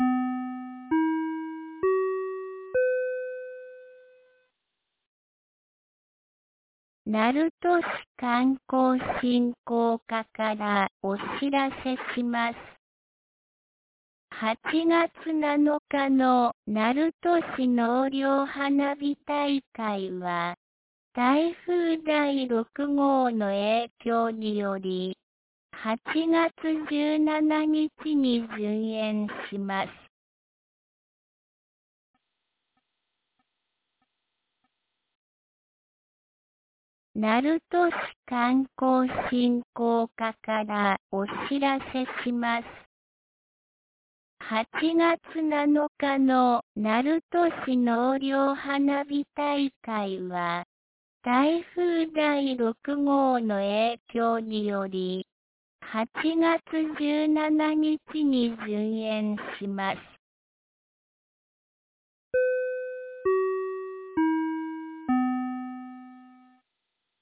2023年08月07日 17時01分に、鳴門市より全地区へ放送がありました。